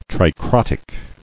[tri·crot·ic]